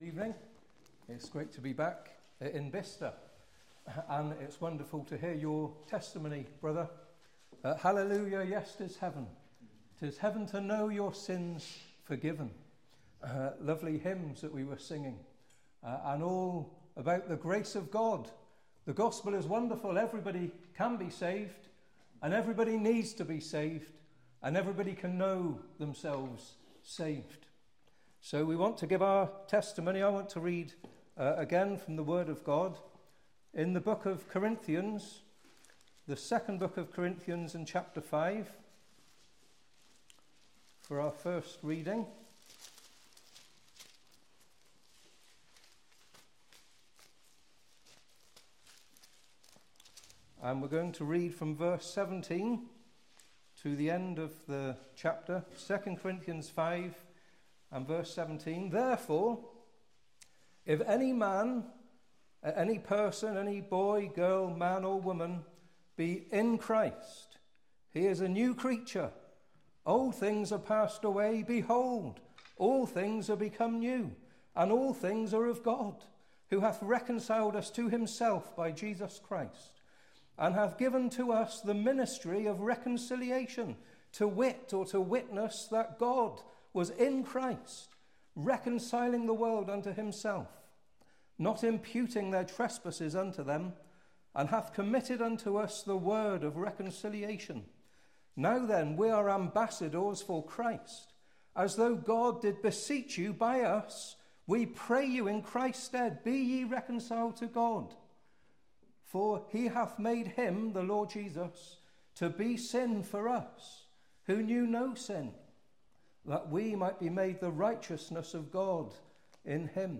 Personal Testimonies